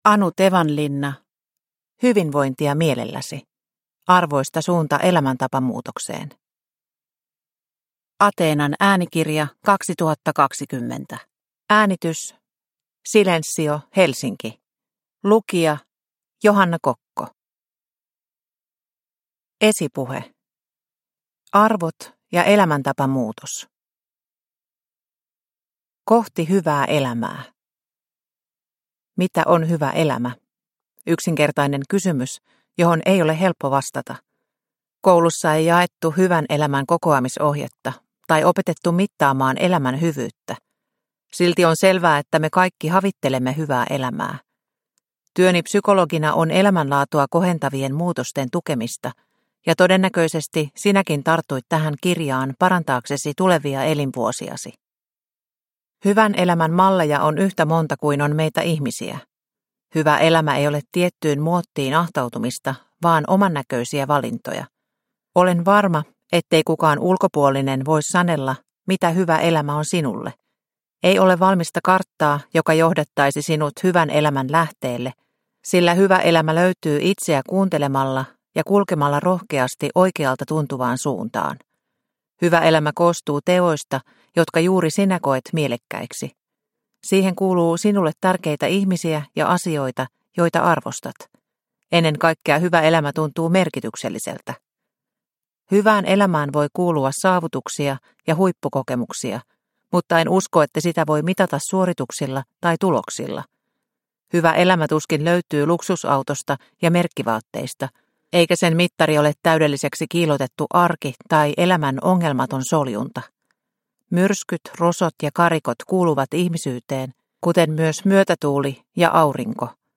Hyvinvointia mielelläsi – Ljudbok – Laddas ner